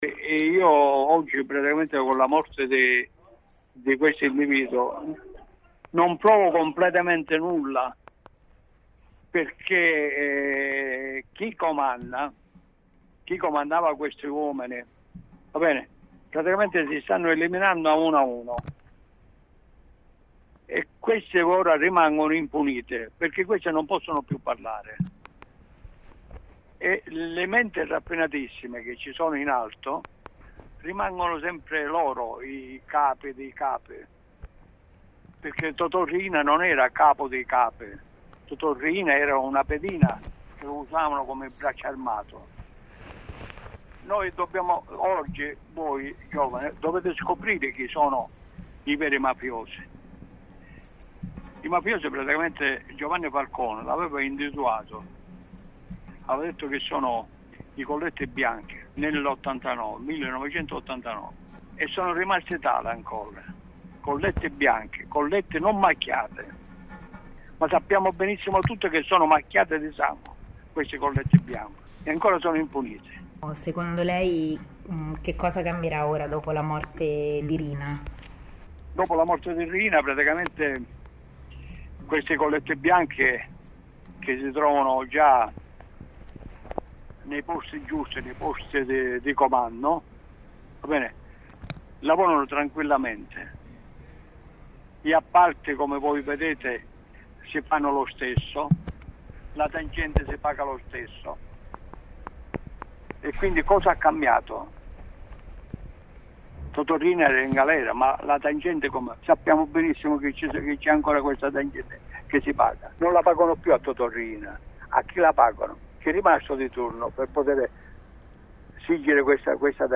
Parla il padre dell'agente ucciso intervistato da Lumsanews